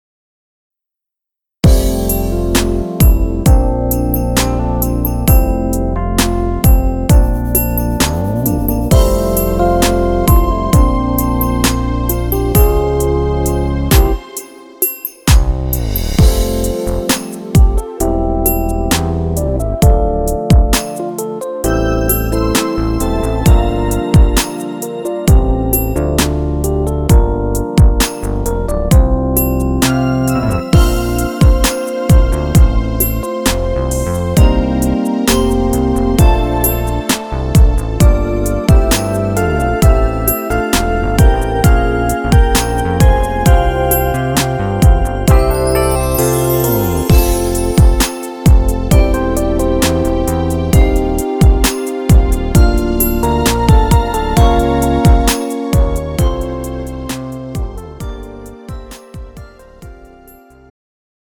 장르 축가 구분 Pro MR